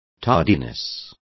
Complete with pronunciation of the translation of tardiness.